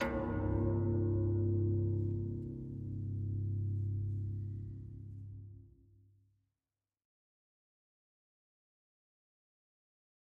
Harp, Deep Single Tone, Type 1